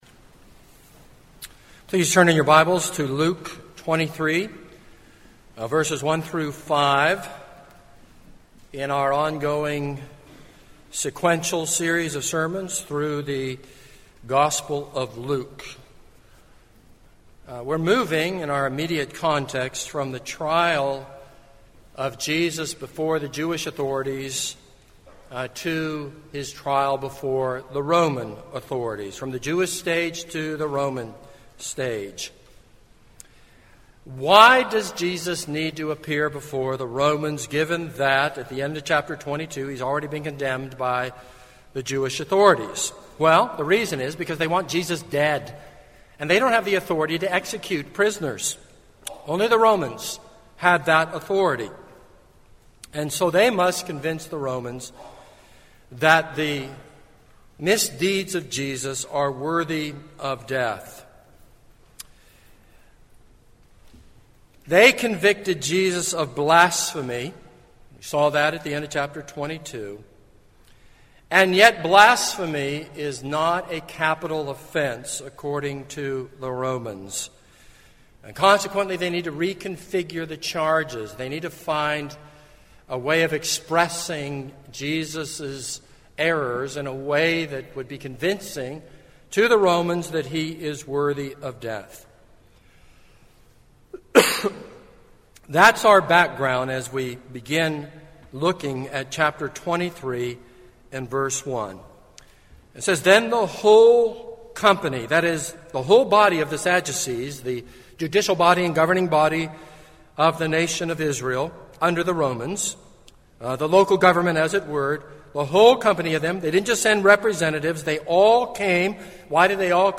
This is a sermon on Luke 23:1-5.